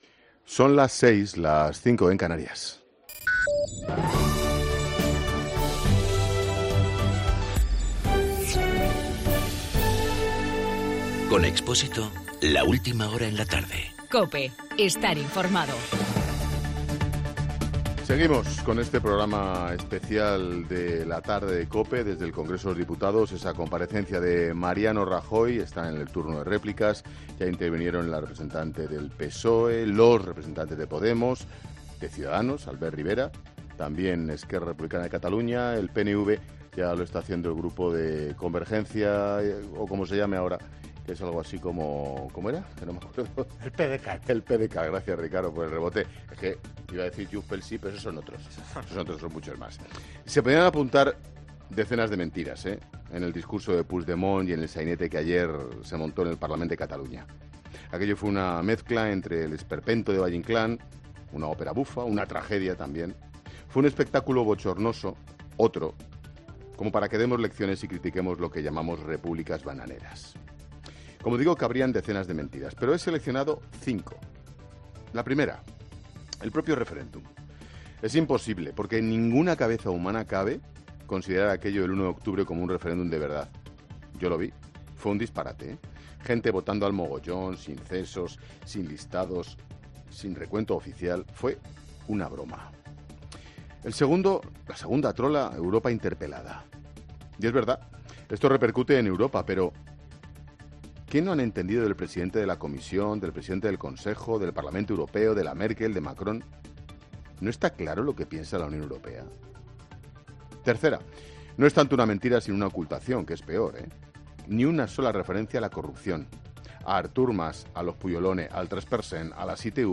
Monólogo de Expósito
Ángel Expósito analiza en su monólogo de las 18h la situación del procés, en directo desde el Congreso de los Diputados.